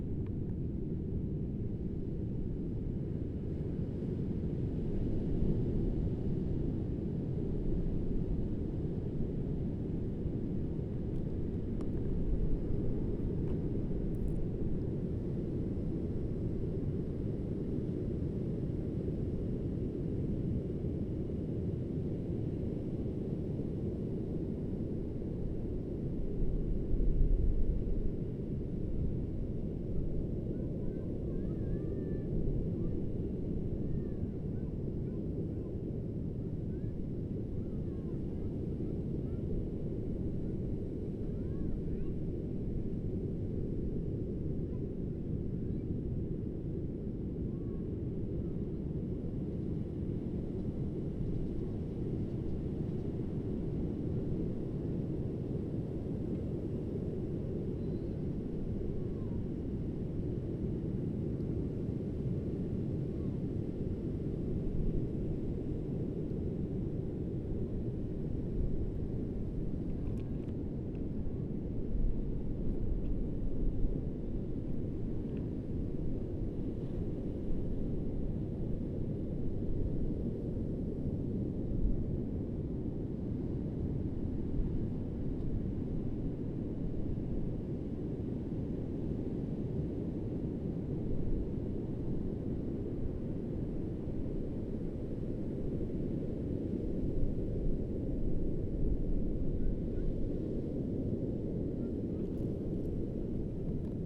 nothing could prepare me for how sudden tipos, the north wind, arrived, screaming through the betel palms and rattling the steel garage doors and house windows. with the shift in wind, we can go diving only the chance days on which the wind sits, hopefully coinciding with the low tide. otherwise, one might go at low tide to collect shellfish along the intertidal zone. yet even then, the waves can intimidate with their sudden canter and crash along the rocks. if you get pulled by the wave, remember to use your urchin hook to hook yourself to a nearby rock!